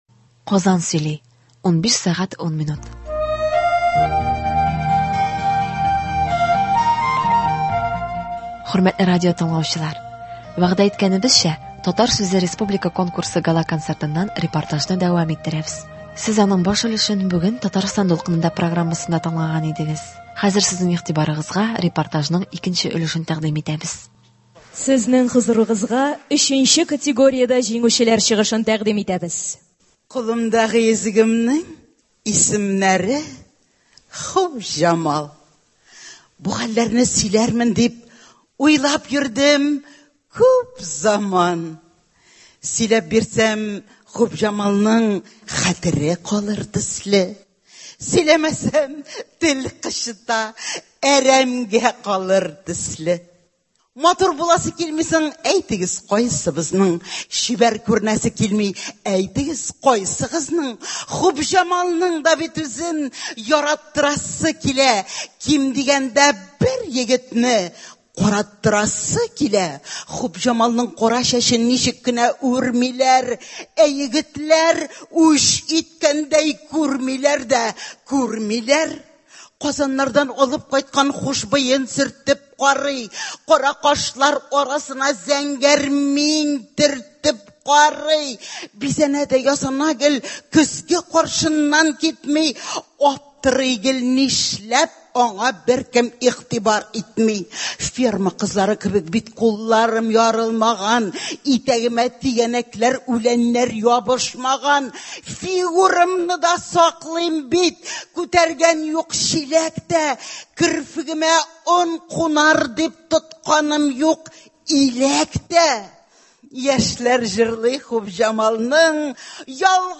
“Татар сүзе” республика конкурсының Гала –концертыннан репортаж.